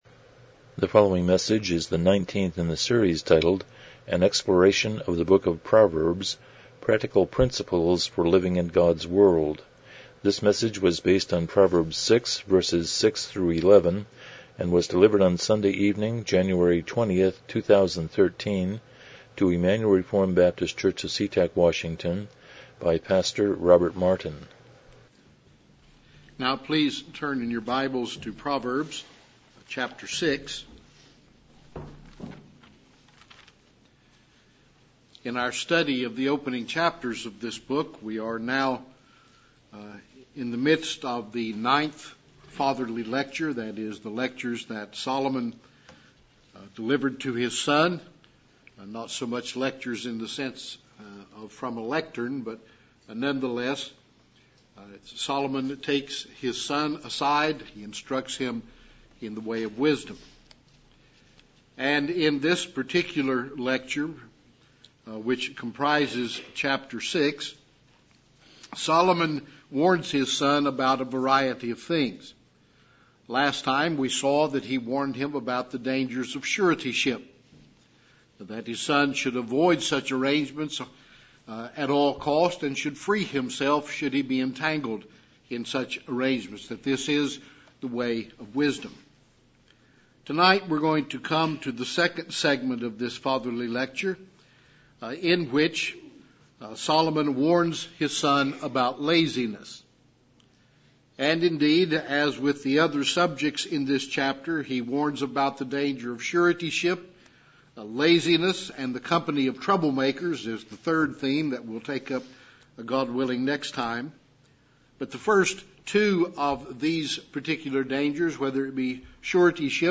Exploration of Proverbs Passage: Proverbs 6:6-11 Service Type: Evening Worship « 22 The Sermon on the Mount